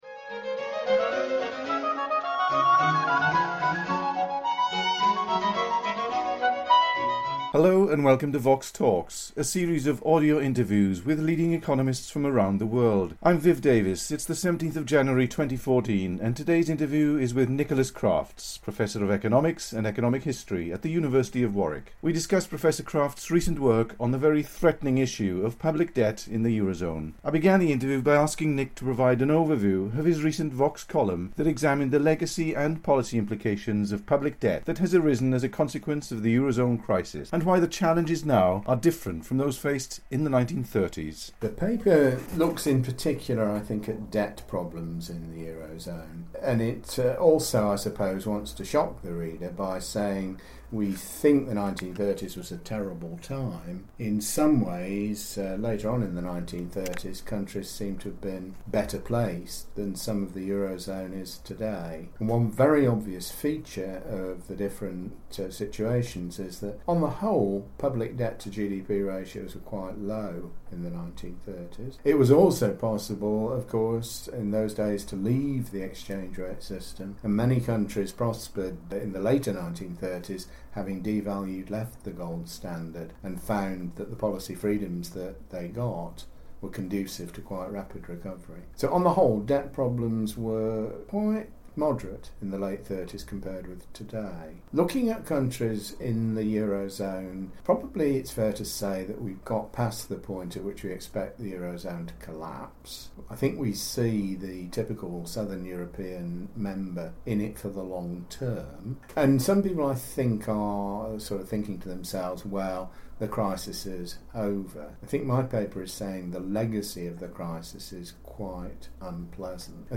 They also discuss the challenges and constraints of banking, fiscal and federal union. The interview was recorded in London on 17 January 2014.